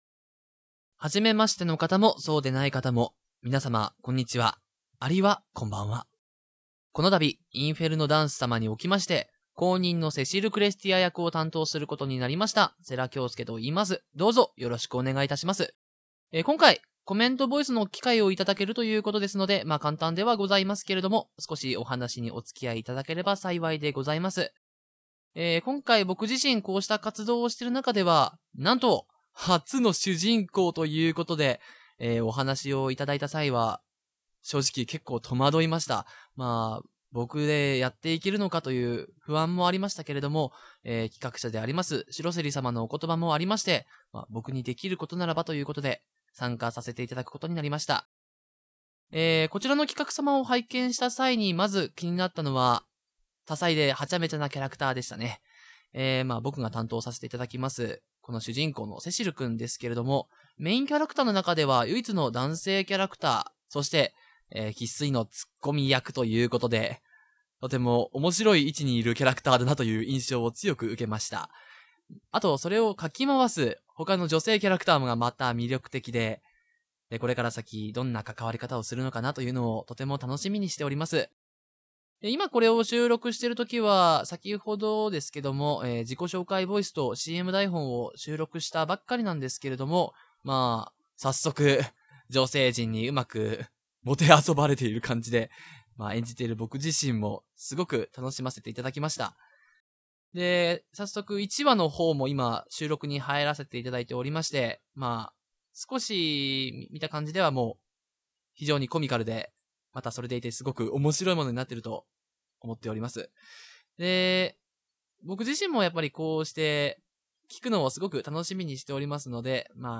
コメントボイス"